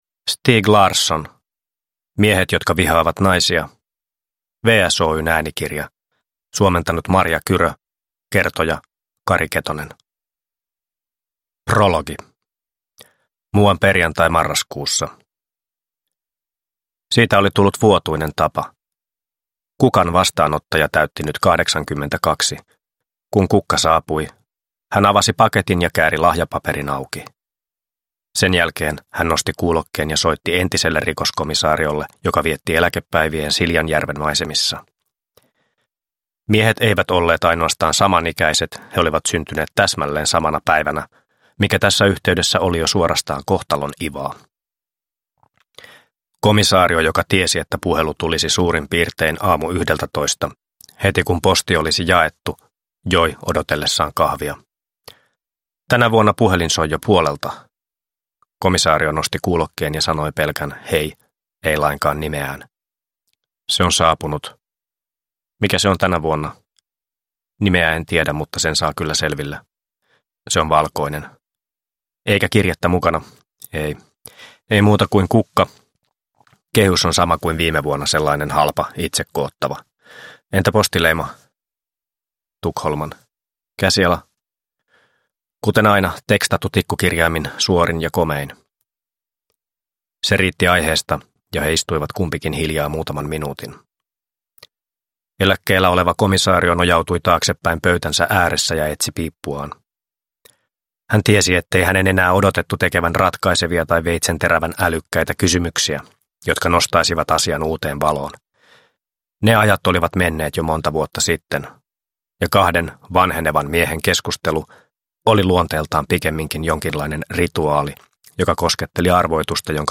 Äänikirjan kertoja, Jussi-palkittu näyttelijä Kari Ketonen,  menee tyylikkäästi legendaarisen työparin Mikael "Kalle" Blomkvistin ja Lisbeth Salanderin nahkoihin.
Uppläsare: Kari Ketonen